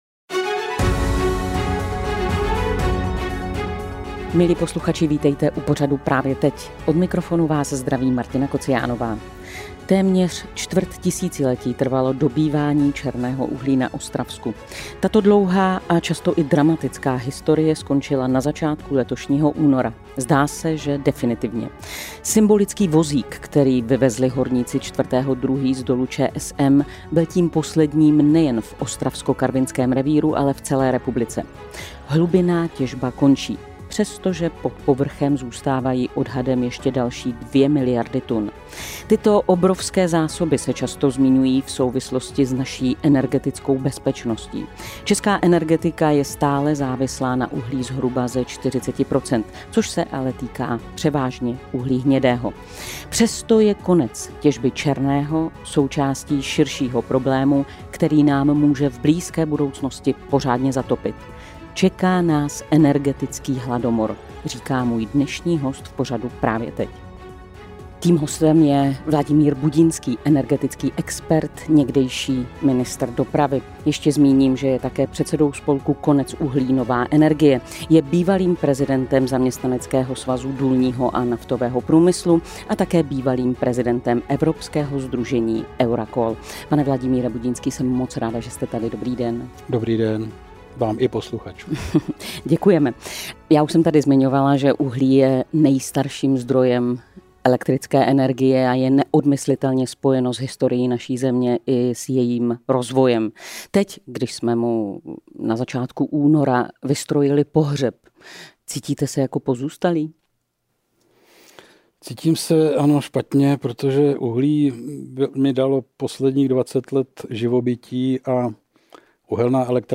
Čeká nás energetický hladomor, říká můj dnešní host v pořadu Právě teď. Tím hostem je Vladimír Budinský, energetický expert, někdejší ministr dopravy.